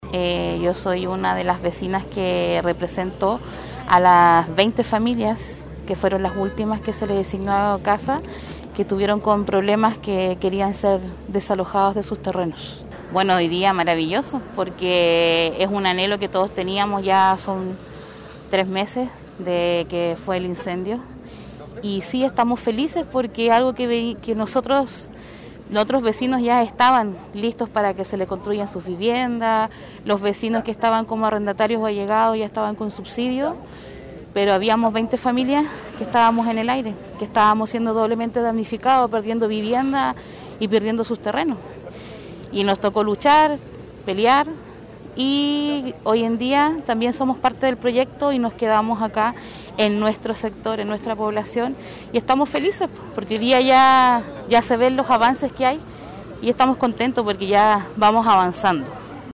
Para ello se llevó a efecto la ceremonia de colocación de la primera piedra, ocasión en que se dio un paso significativo para que las 144 familias afectadas puedan comenzar a rehacer sus vidas.